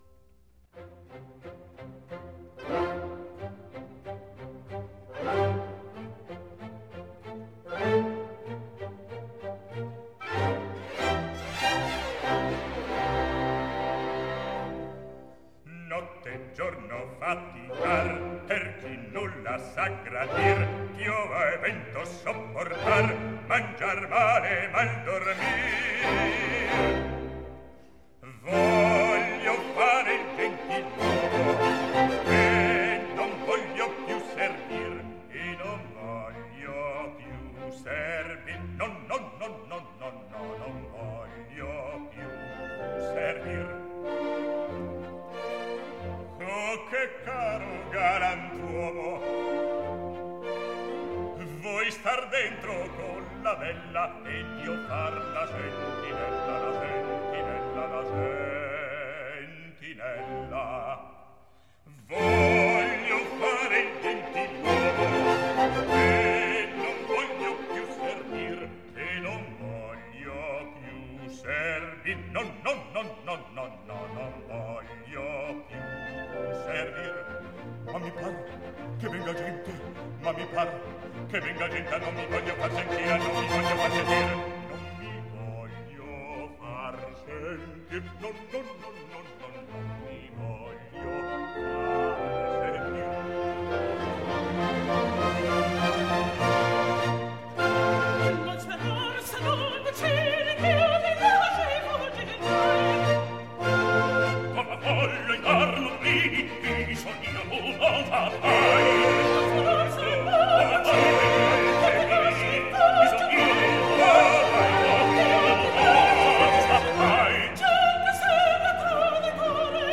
Opera buffa
1706 - 1850 (Baroque, Classical)